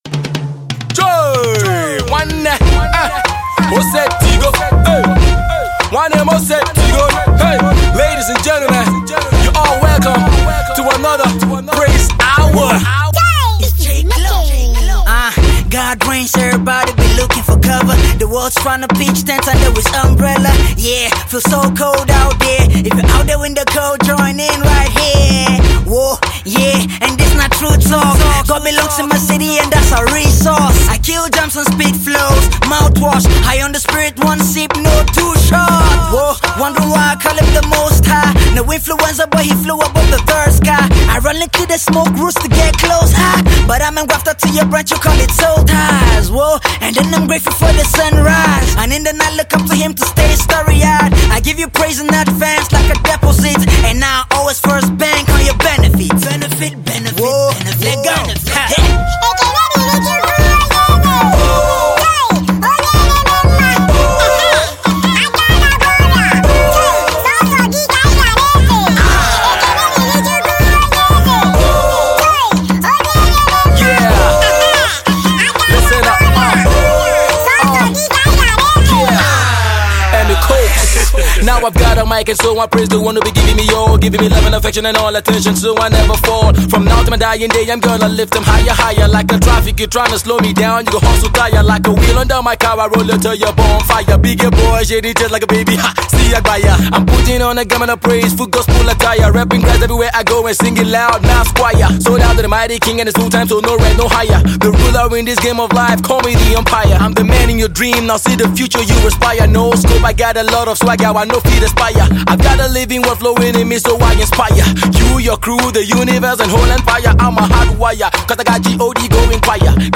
He made this beat in 5 minutes.
Christian Hip Hop
nigerian gospel music